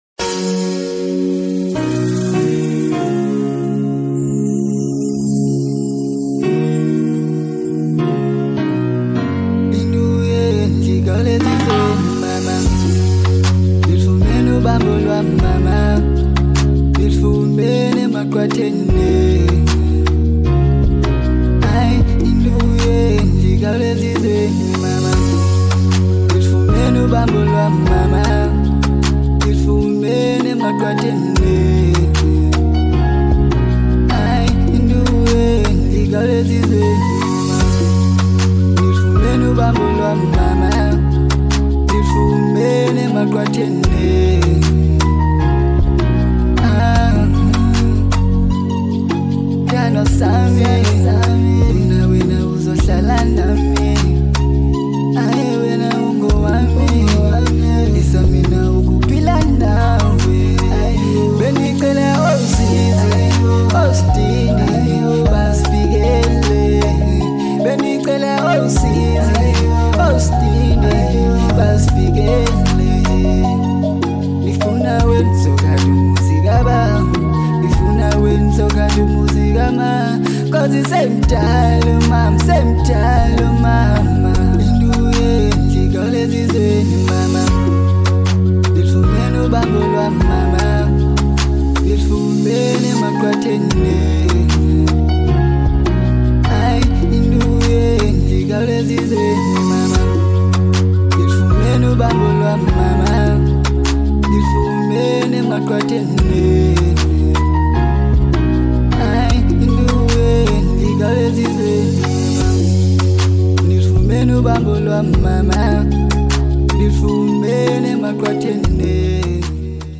Genre : Afro Pop